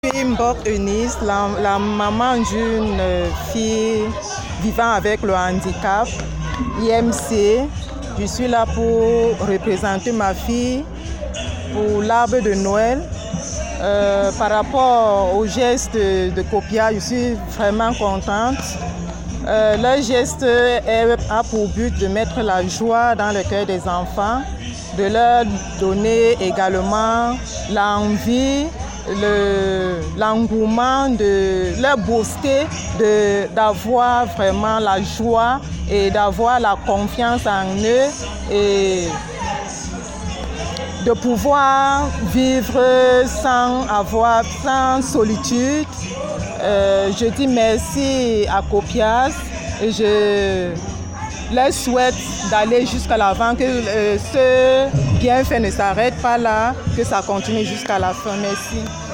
Cameroun- COPIASEH : Une cérémonie de l’Arbre de Noël dédiée aux enfants en situation de handicap